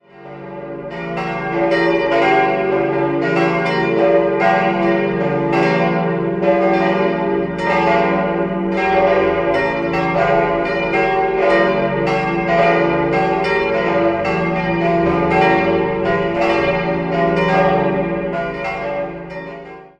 Idealquartett: d'-f'-g'-b' Die drei größeren Glocken wurden 1950 vom Bochumer Verein für Gusstahlfabrikation gegossen, die kleine stammt von den Gebrüdern Ulrich (Kempten) aus dem Jahr 1929.